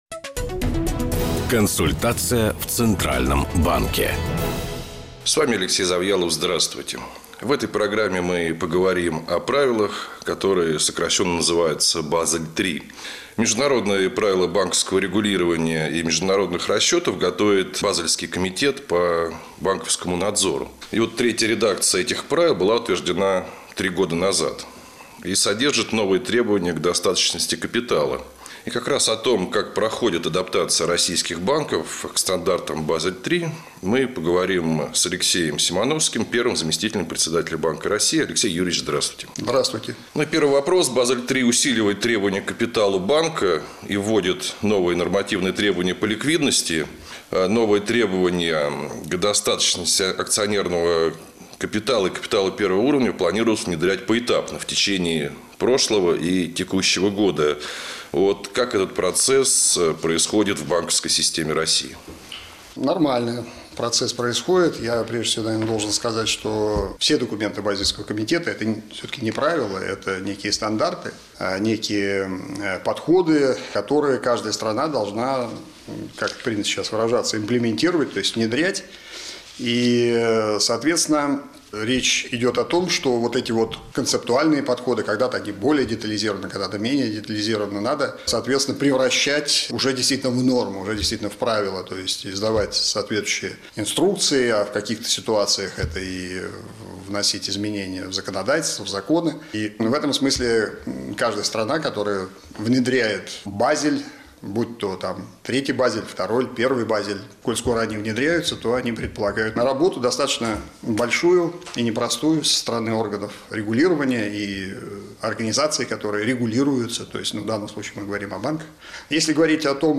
Интервью
Интервью первого заместителя Председателя Банка России А.Ю. Симановского радиостанции «Бизнес ФМ» 31 октября 2014 года (часть 1)